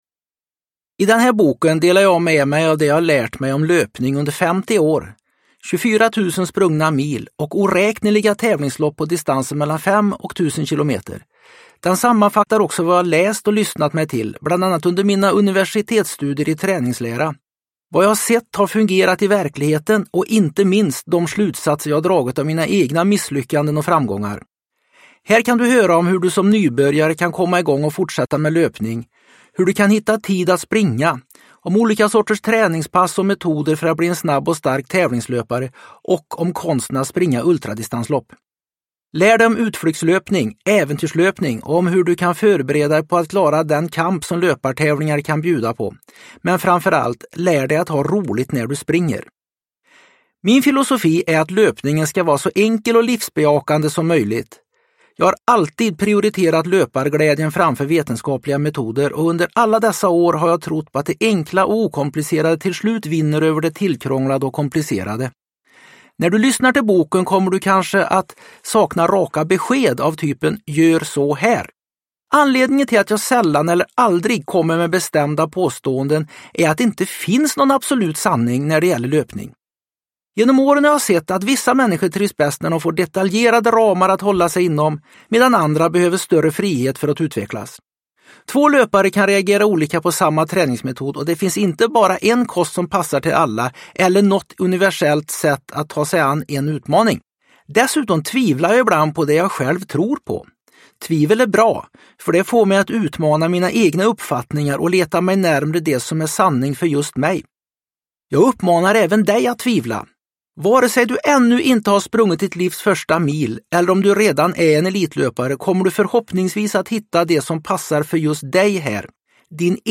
Löparglädje – Ljudbok – Laddas ner